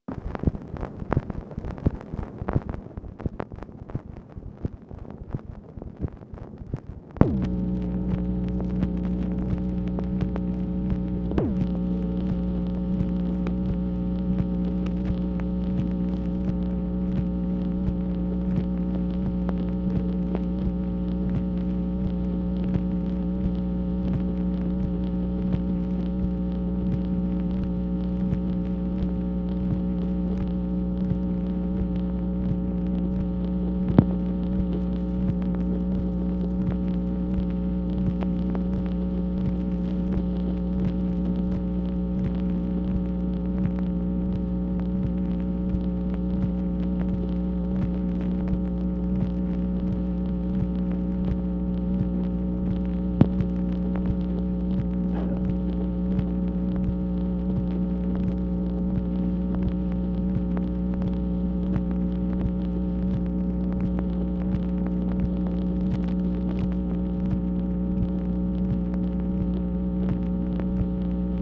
MACHINE NOISE, November 14, 1966
Secret White House Tapes | Lyndon B. Johnson Presidency